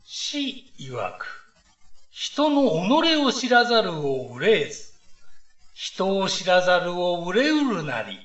下の ＜朗読音声＞ をクリック又はタップすると、朗読音声が流れます。